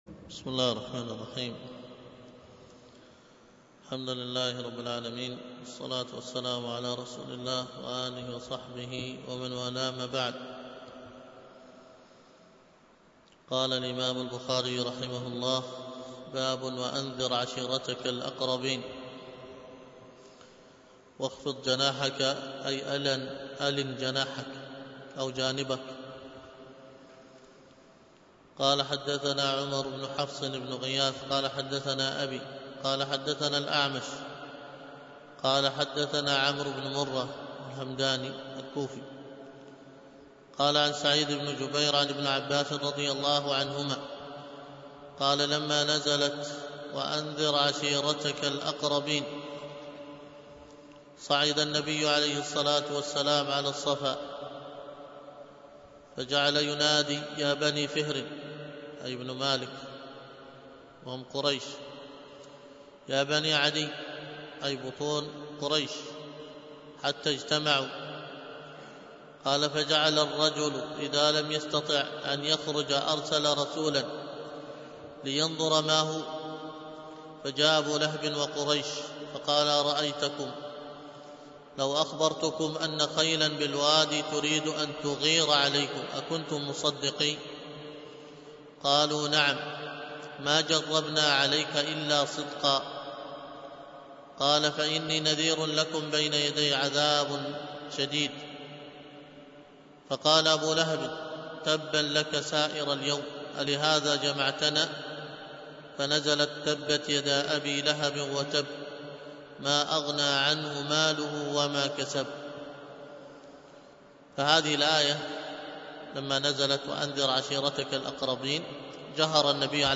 الدرة البهية شرح القصيدة التائية 18 تحميل الدرس في الدرة البهية شرح القصيدة التائية 18، الدرس الثامن عشر:من( وشبيه بهذا أيضاً قول الشيخ:وتقدير رب الخلق للذنب موجب ...